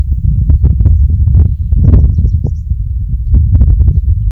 Caminheiro-de-unha-curta (Anthus furcatus)
Nome em Inglês: Short-billed Pipit
Detalhada localização: Camino a El Duraznal
Condição: Selvagem
Certeza: Fotografado, Gravado Vocal